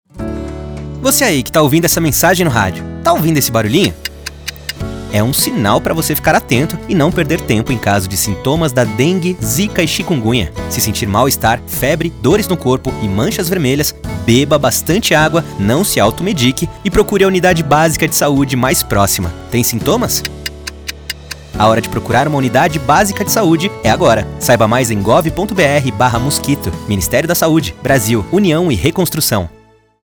Áudio - Spot 30seg - Sintomas - Mosquito - 1,14mb .mp3 — Ministério da Saúde